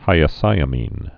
(hīə-sīə-mēn)